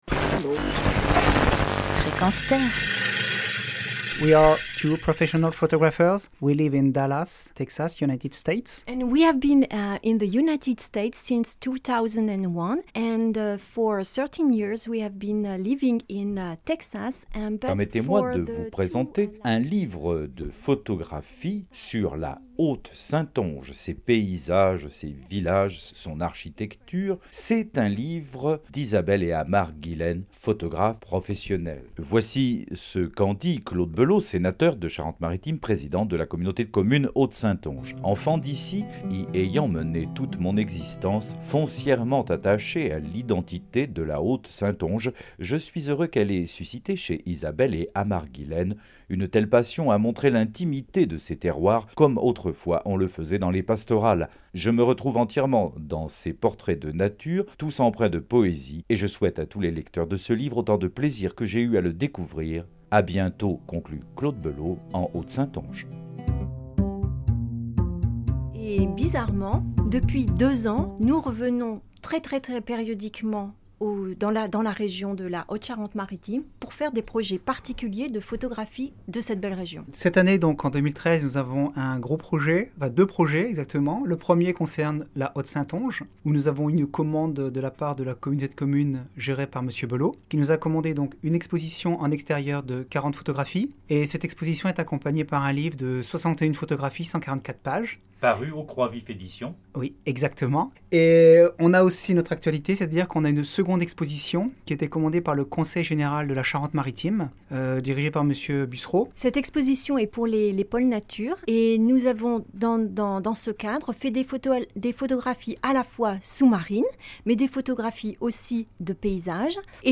Notre interview pour la radio Frequence Terre.